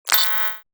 UI_SFX_Pack_61_27.wav